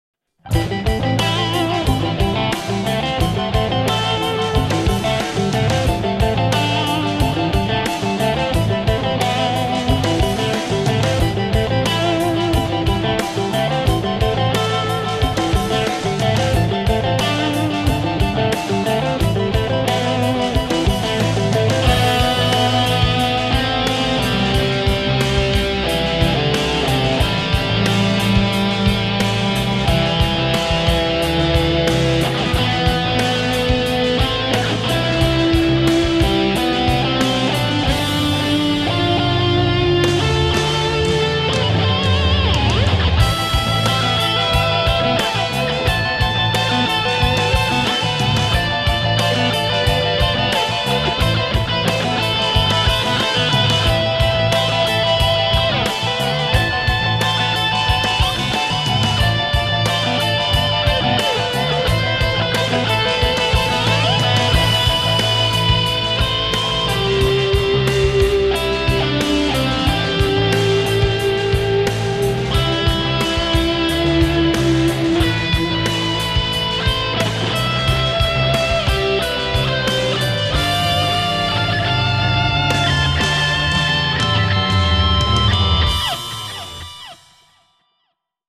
[Pop]